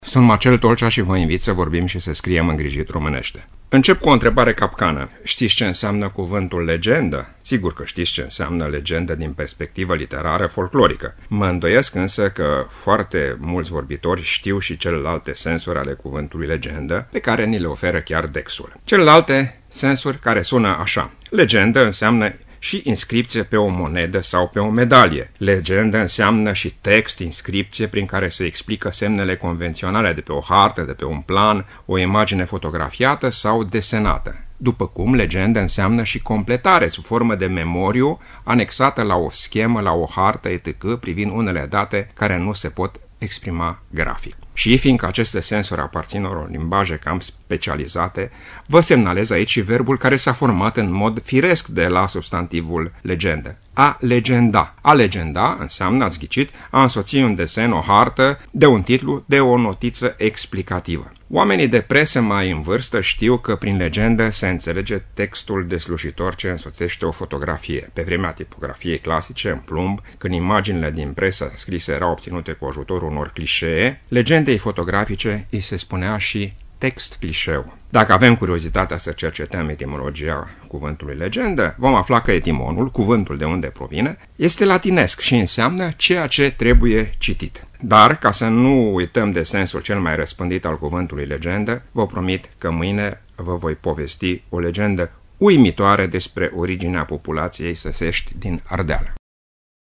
(rubrică difuzată în 28 iunie 2017)